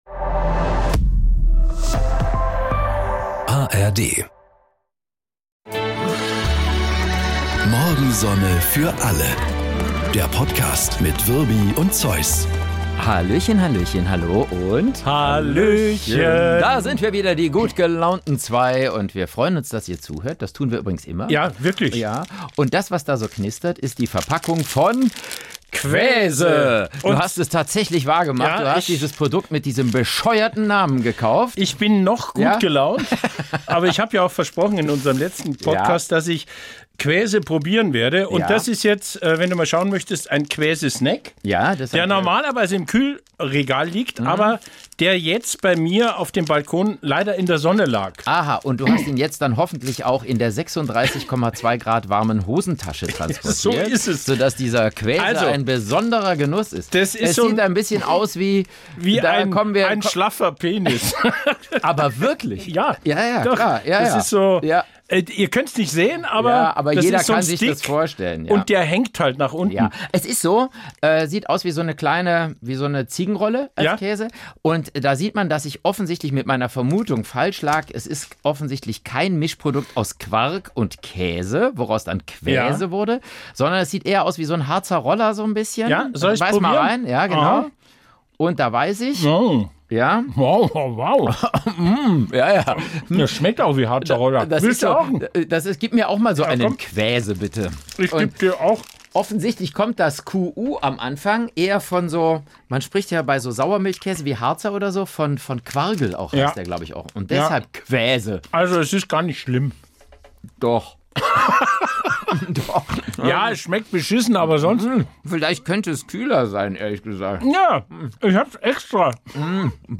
Und sind damit noch munterer für diesen Podcast: Ein großer, dicker Bayer und ein kleiner, schmaler Rheinländer machen große, dicke Gags und kleine, schmale Gemeinheiten.
Komödie